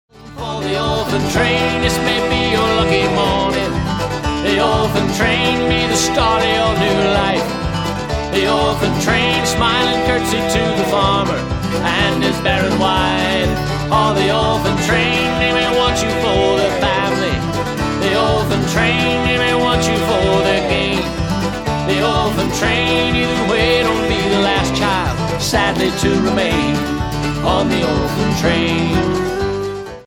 12 original Celtic tunes